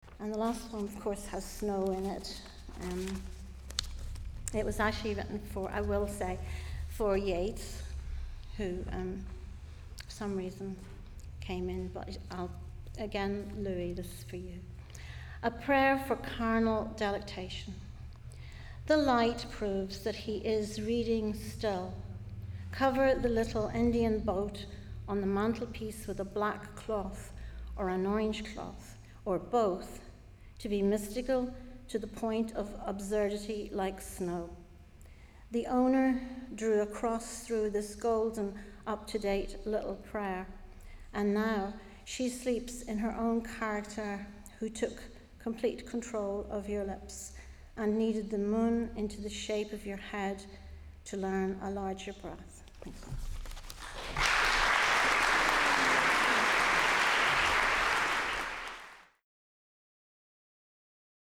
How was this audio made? The following recordings were made at the Louis MacNeice Centenary Celebration and Conference at Queen's University, Belfast in September 2007.